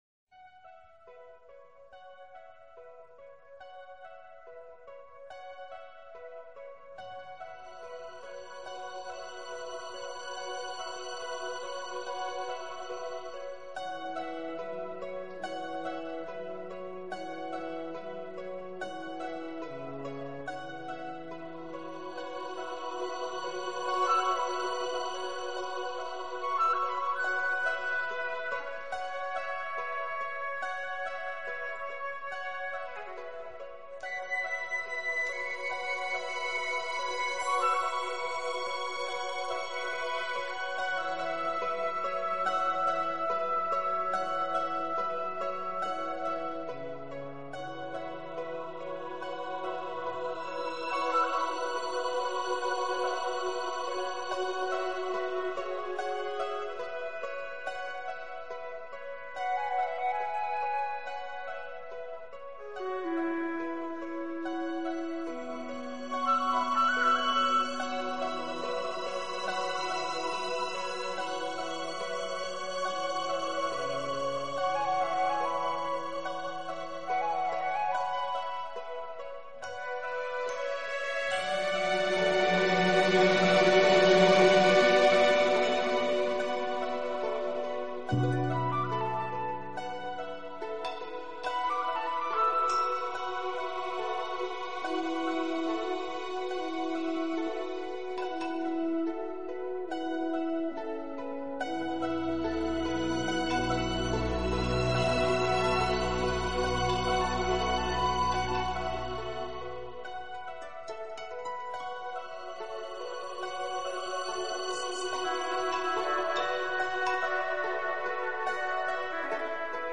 专辑语言：纯音乐
这种音乐是私密的，轻柔的，充满庄严感并总是令人心胸开阔。
实为New Age音乐发展中令人惊喜的成果。